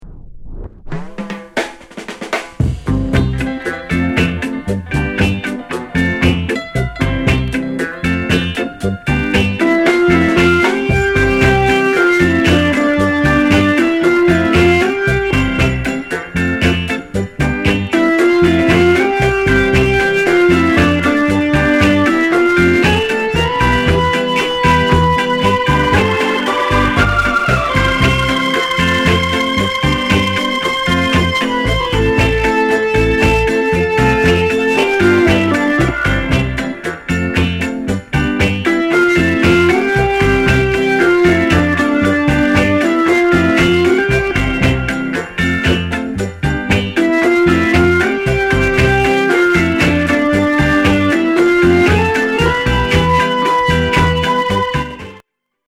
NICE ROCKSTEADY INST